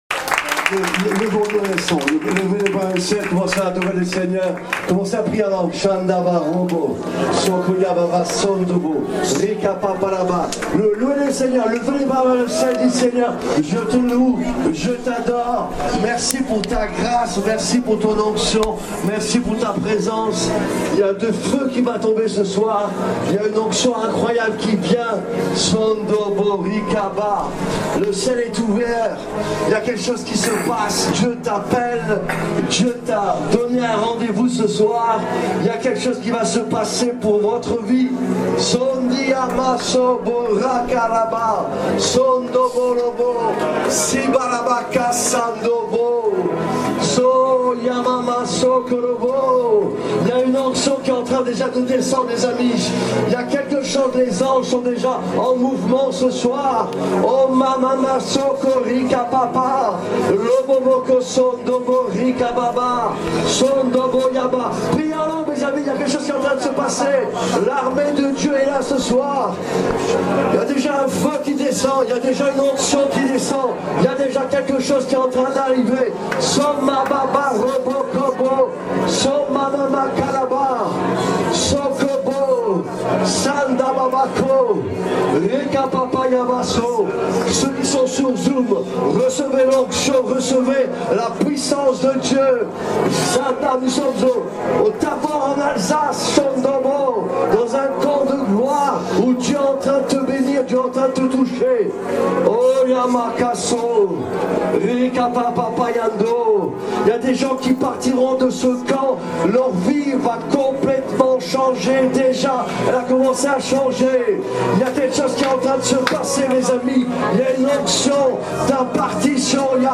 Le camp 2021 au Tabor en Alsace a été exceptionnel: